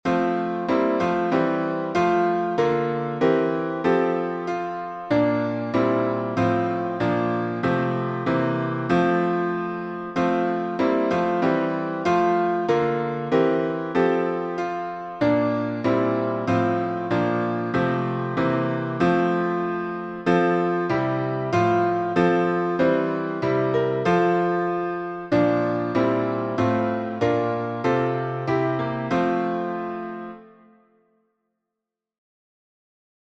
Key signature: F major (1 flat) Time signature: 4/4 Meter: 7.7.7.7.7.7. Public Domain 1.